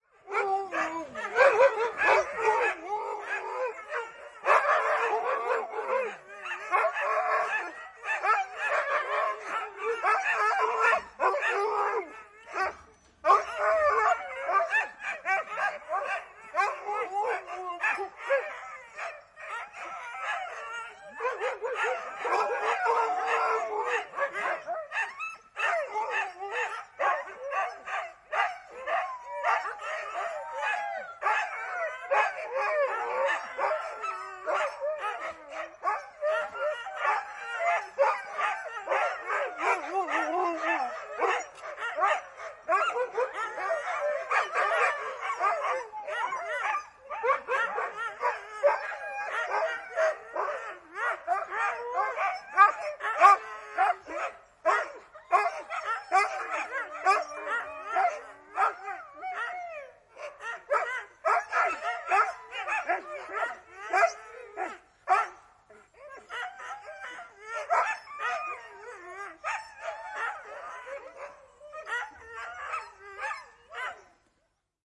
宠物动物 " 狗，雪橇犬，吠叫和嚎叫，西伯利亚雪橇犬
描述：Siperianhusky，lauma koiria haukkuu ja uliseeennenähtöä，ulvoo ulkona。 Äänitetty/ Rec：Analoginen nauha，Nagra / Analog tape，Nagra Paikka /地方：Suomi /芬兰/埃斯波，HanasaariAika /日期：28.02.1987
标签： Vetokoira 现场录音 Talvi 动物 Koira Yleisradio YLE Tehosteet 芬兰 芬兰语 芬兰广播公司 冬季 Soundfx 雪橇狗
声道立体声